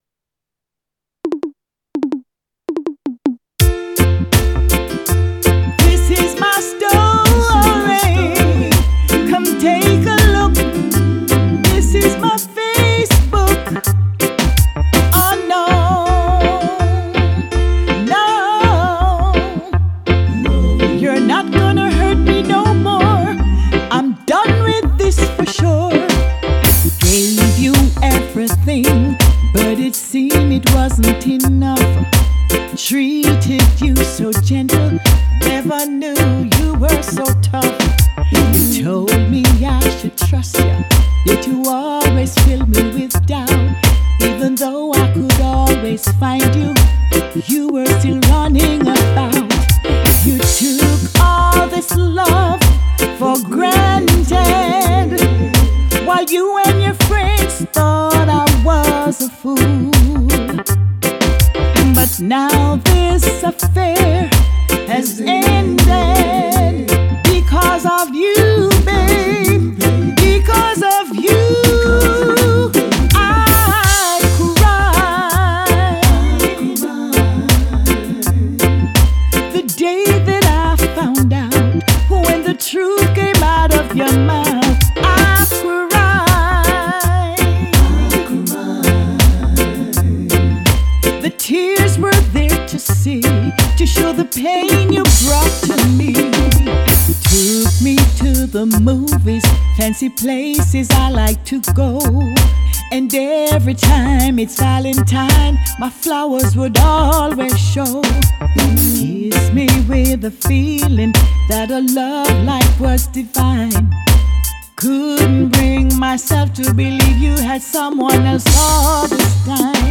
reggae riddim